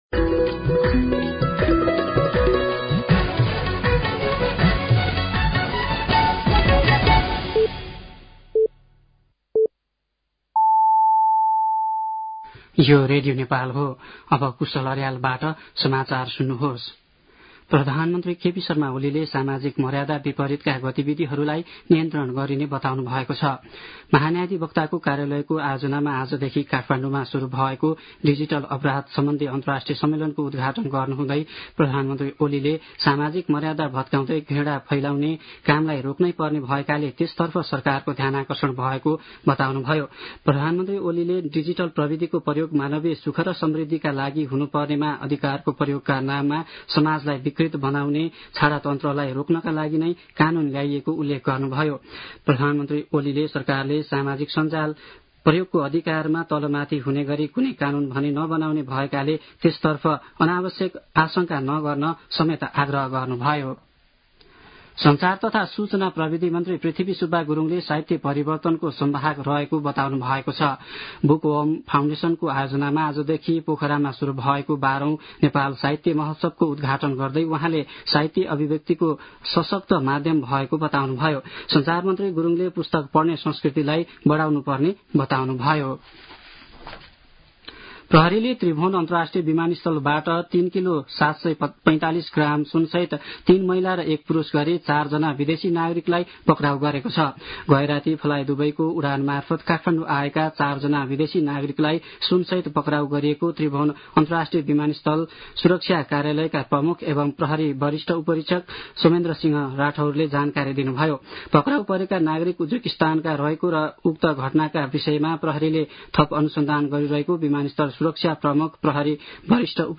साँझ ५ बजेको नेपाली समाचार : १६ फागुन , २०८१
5-pm-news-4.mp3